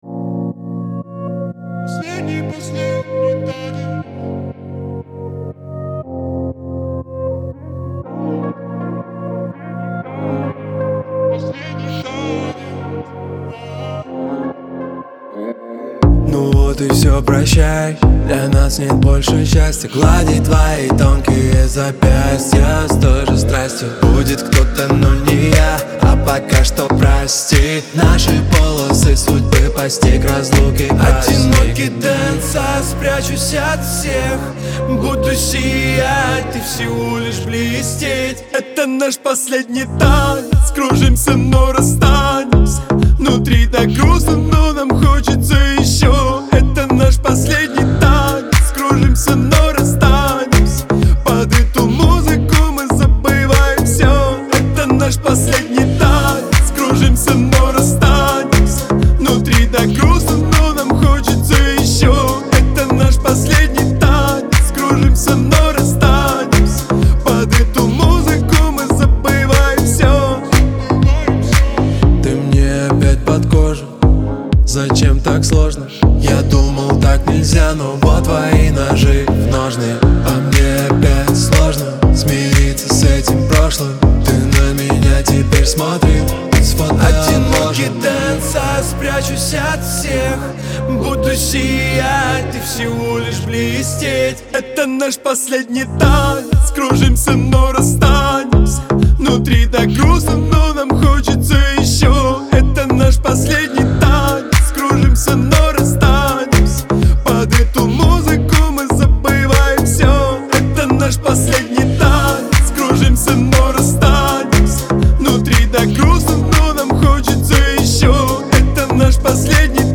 выполненная в жанре поп с элементами электроники.